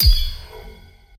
Song: Dsgnmisc Kill Confirm Metallic 04 Sfrms Sciwpns